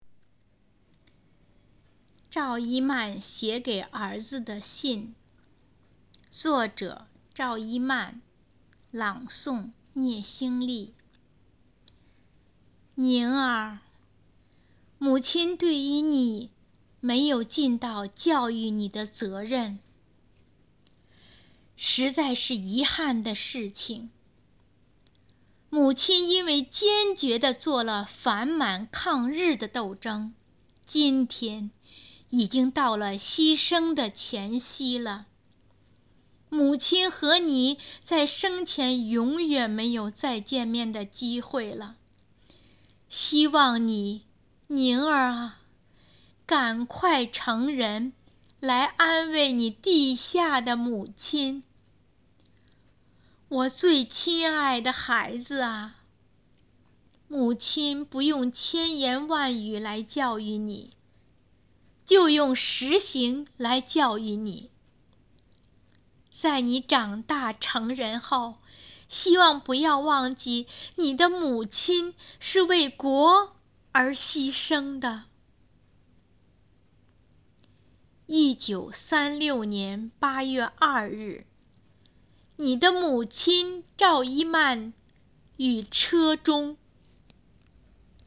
《赵一曼写给儿子的信》独诵